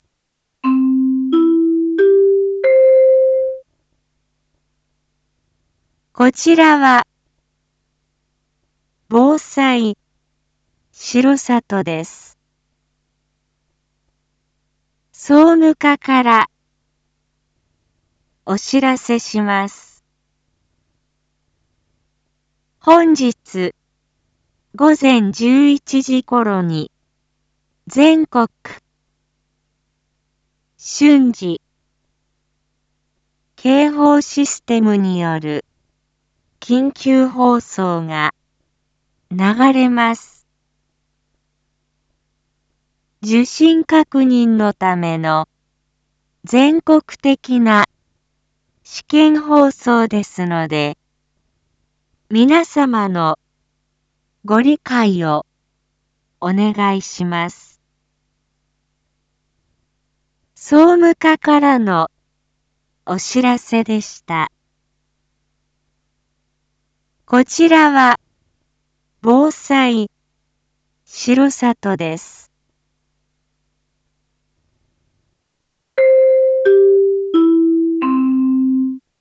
一般放送情報
Back Home 一般放送情報 音声放送 再生 一般放送情報 登録日時：2024-02-09 07:01:21 タイトル：R6.2.9 全国瞬時警報システムによる試験放送について インフォメーション：こちらは防災しろさとです。